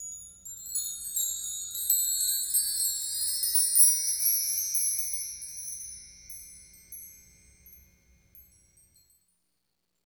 Index of /90_sSampleCDs/Roland L-CD701/PRC_Wind chimes/PRC_Windchimes